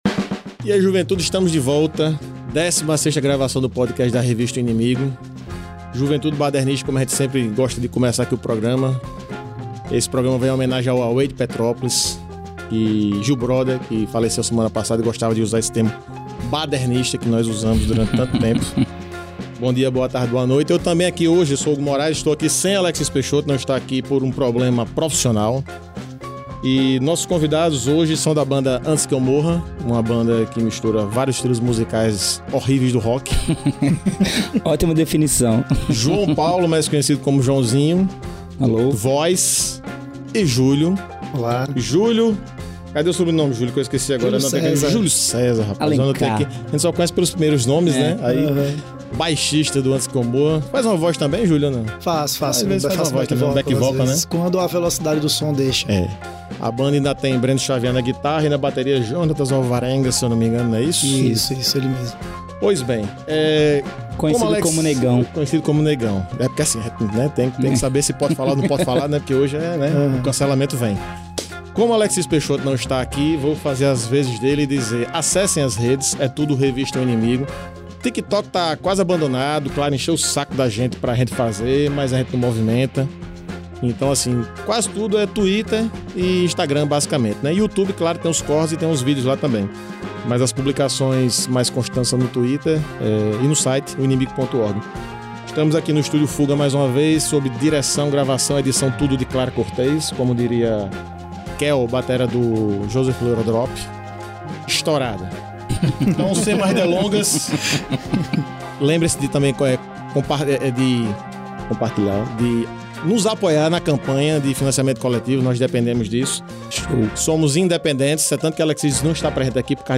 Na entrevista – que, salvo engano, é a primeira entrevista longa concedida pelo grupo! – a dupla fala sobre o trabalho de composição e gravação do novo disco, que traz mais do sarcasmo e crítica social que sempre marcou o trabalho da Antiskieumorra, mas também aborda questões pessoais, como saúde mental, paternidade, memória, amizade entre outros temas.
revista-o-inimigo-entrevista-antiskieumorra.mp3